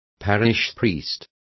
Also find out how parroco is pronounced correctly.